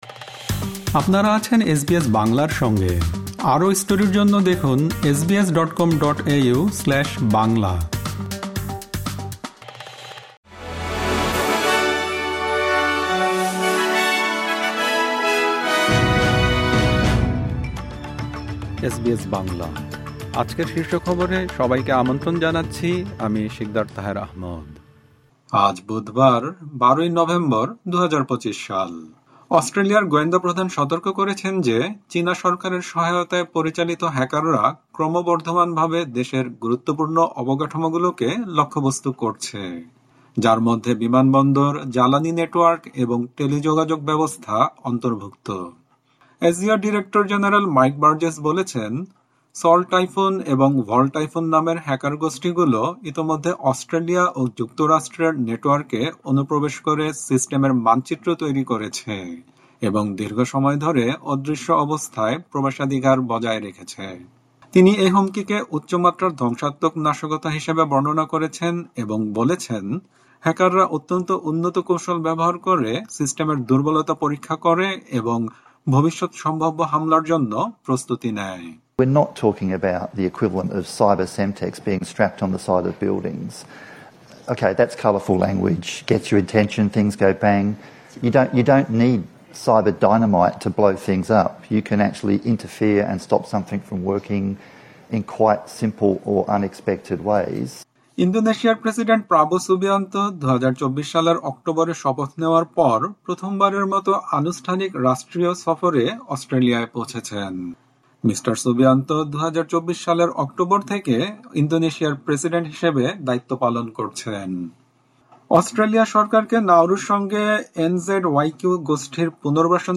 এসবিএস বাংলা শীর্ষ খবর: ১২ নভেম্বর, ২০২৫